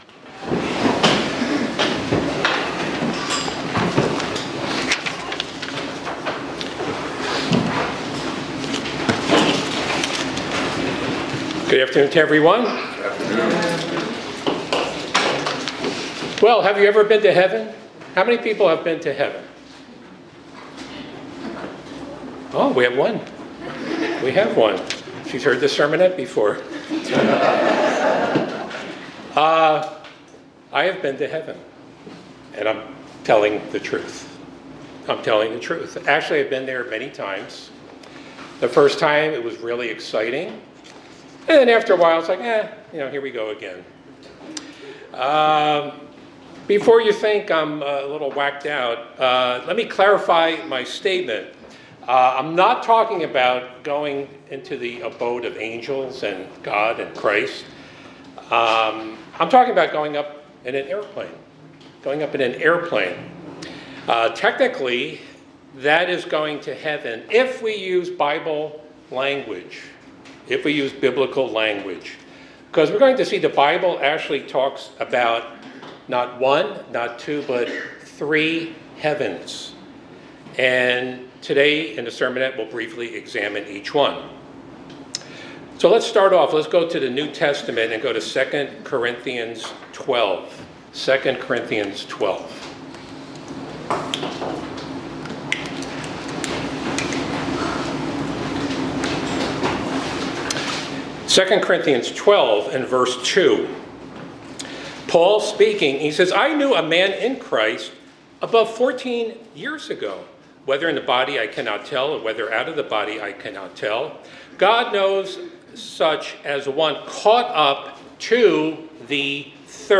This sermon discusses the concept of three heavens as described in the Bible. It utilizes biblical language to explain their meanings and provides an understanding of the context of each as mentioned in the Bible.